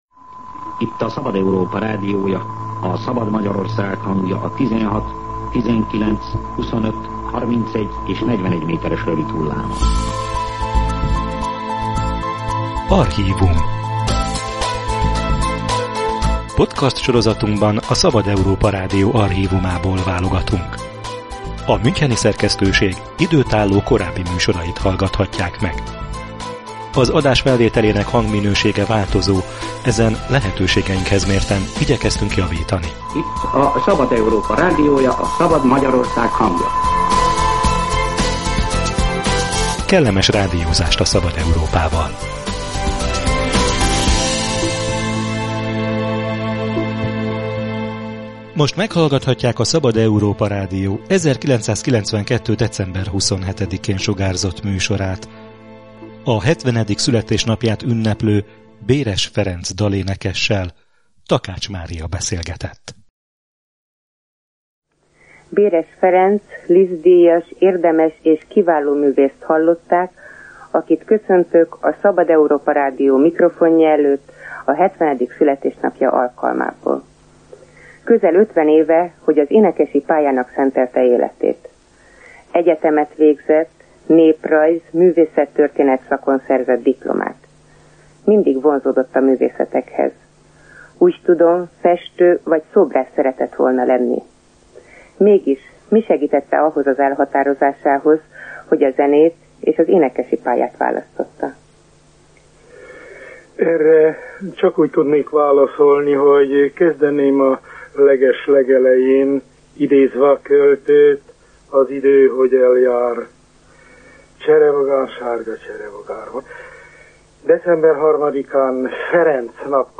„Nótával jöttem a világra” – archív beszélgetés Béres Ferenc népdalénekessel